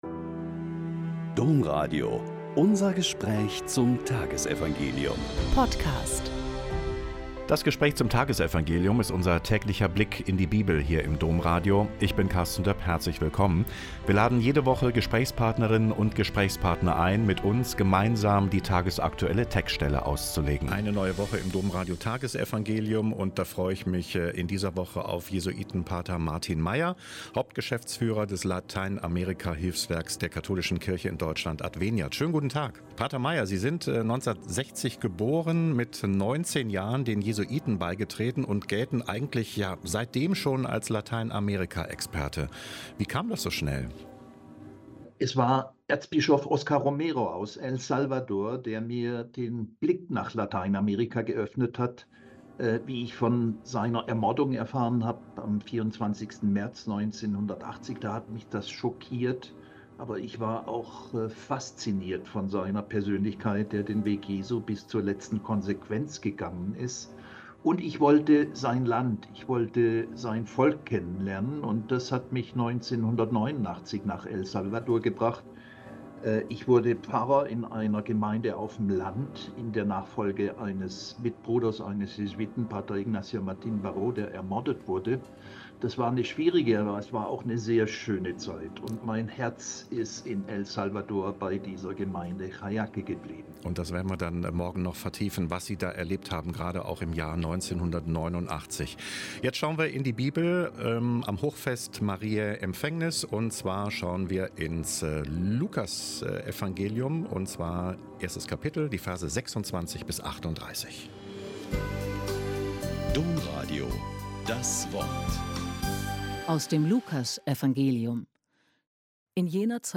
Lk 1,26-38 - Gespräch